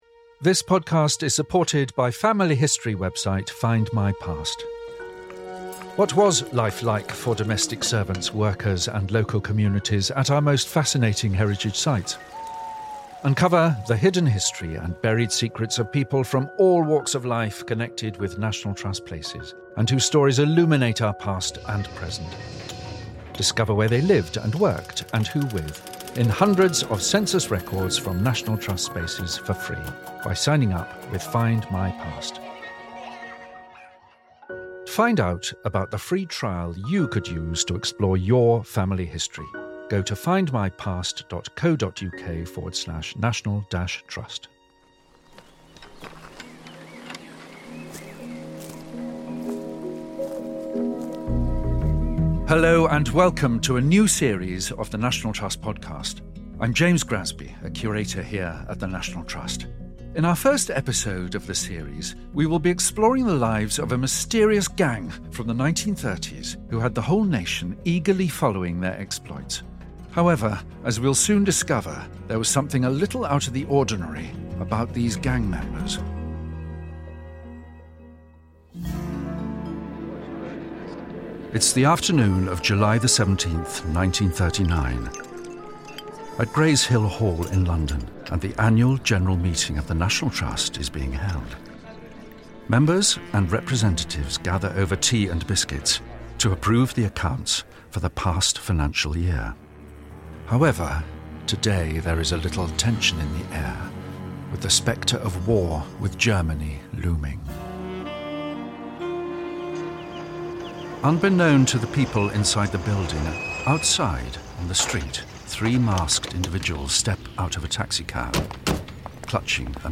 They were mask-wearing, all-women activists who gave money in quirky ways to help save the countryside. In this docudrama, uncover the identities of the people behind the secretive Ferguson's Gang, and unravel a tale of intrigue, mischief and mayhem that made a lasting impact on British heritage.